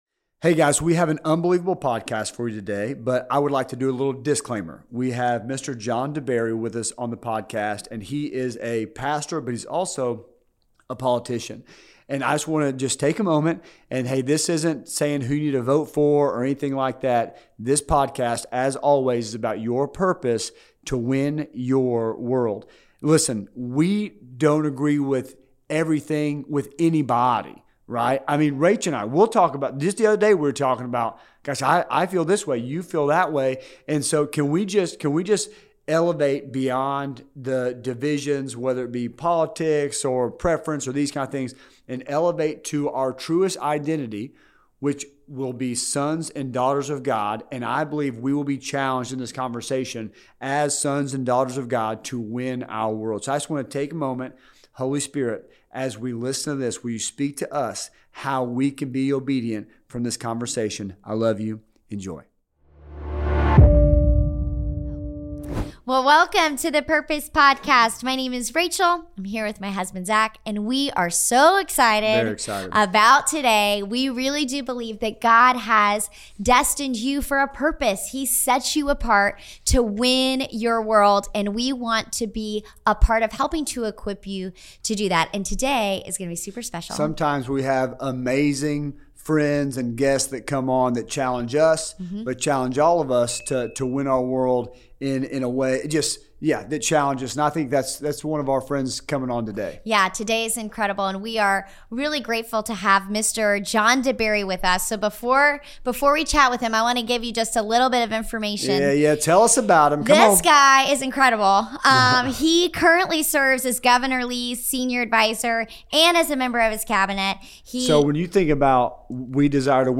They dive into a conversation that transcends politics and focuses on living out one's true identity as sons and daughters of God.
Tune in for a deep, challenging discussion that inspires spiritual and personal growth, urging believers to actively live their faith in today's divided world.